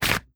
Leather Holster 003.wav